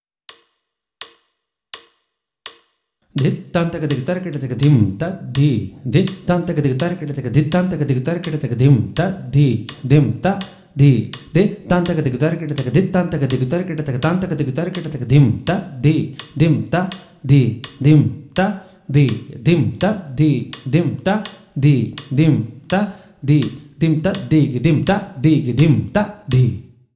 This is a mukthayam of 32 beats, which is a combination of both chaturashra nade and trishra nade.
Konnakol